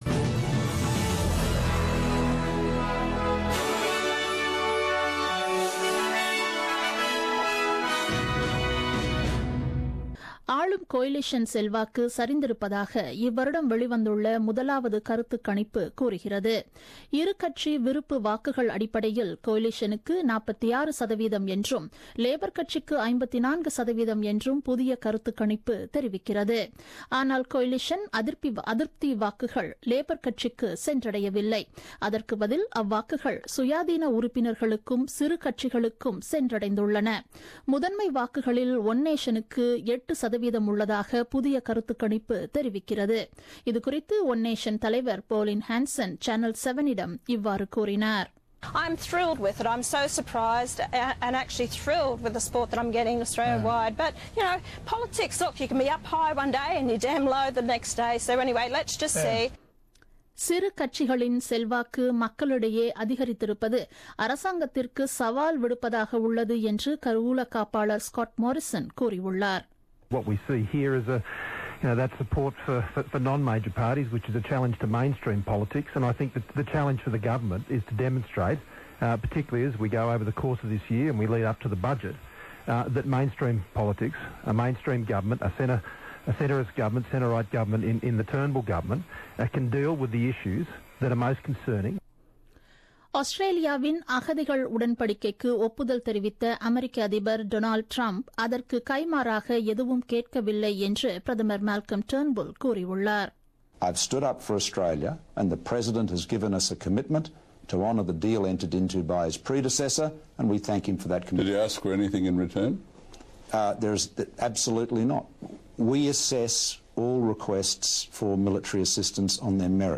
The news bulletin broadcasted on 06 Feb 2017 at 8pm.